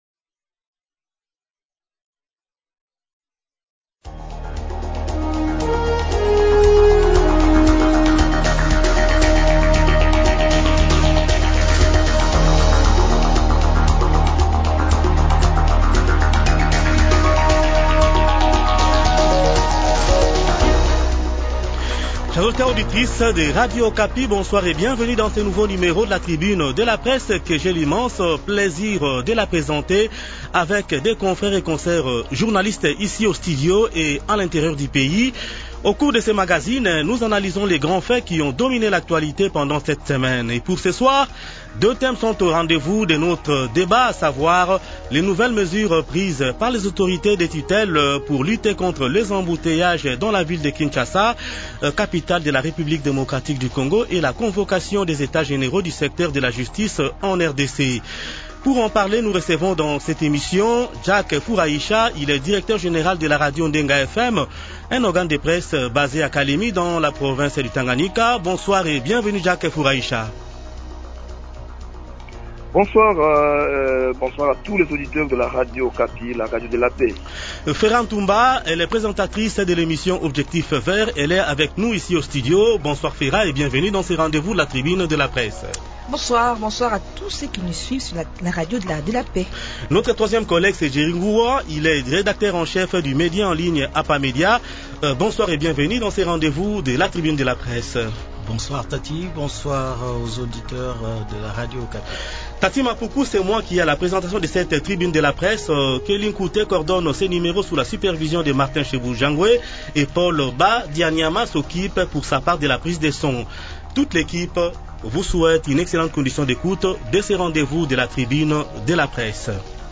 Comment commentez-vous ces deux sujets ayant dominés l'actualités cette semaine ? Invités :